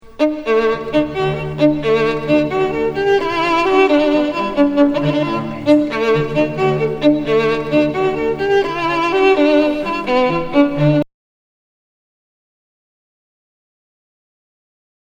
The track used is a loop